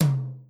tom1.wav